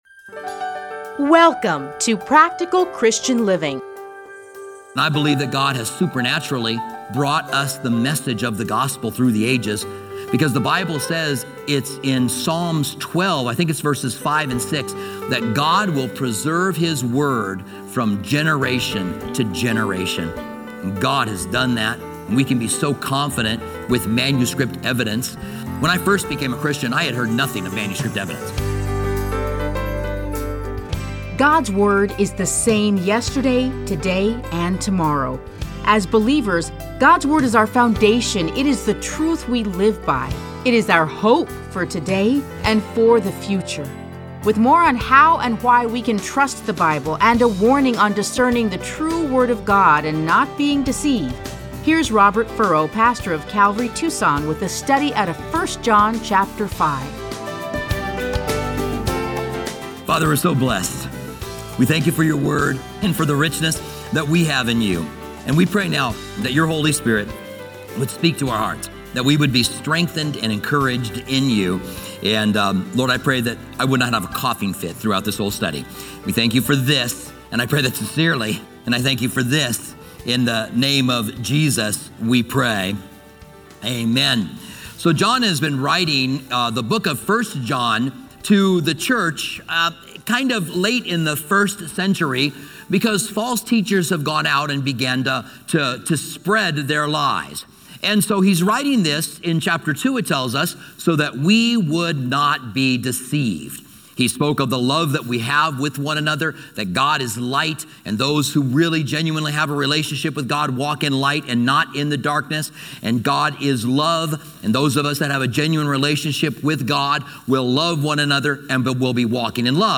Listen to a teaching from 1 John 5:6-12.